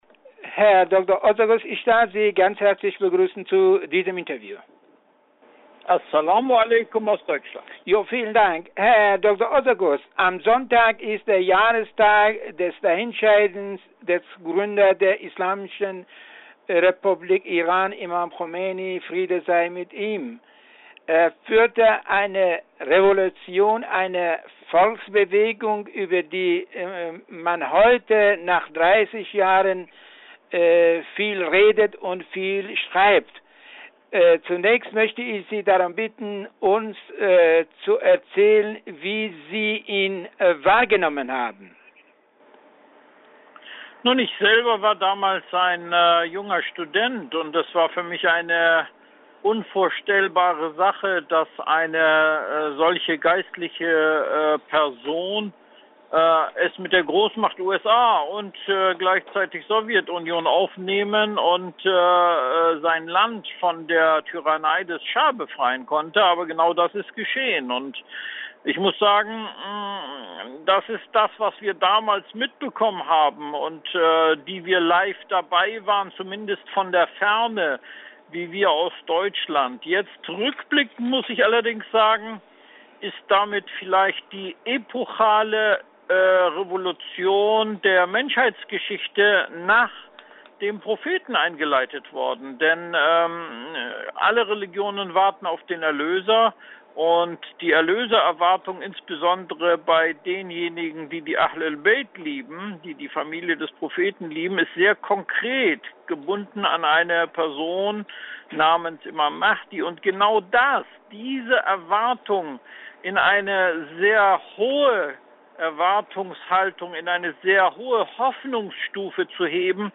Anmerkung: Das Interview in voller Läne im Audio!